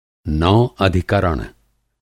Aprenda Hindi com falantes nativos